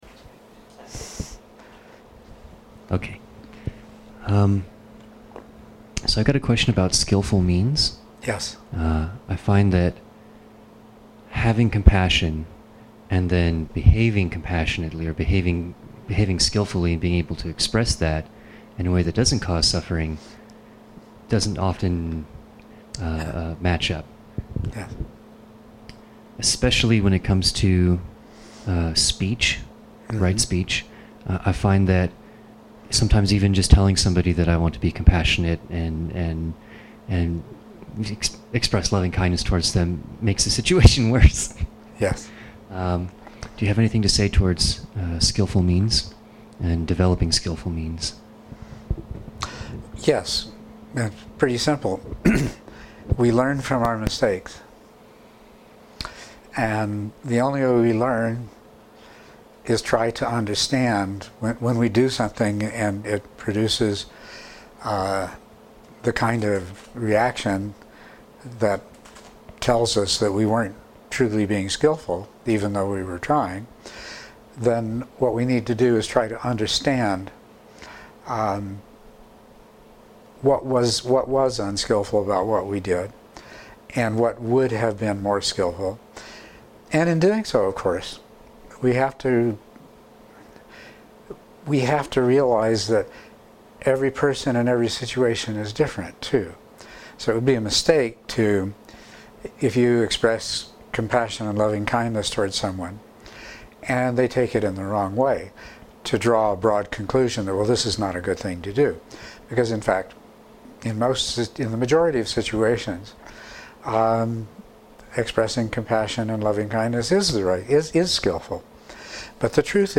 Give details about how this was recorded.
MP3 of the Dharma talk at the Stronghold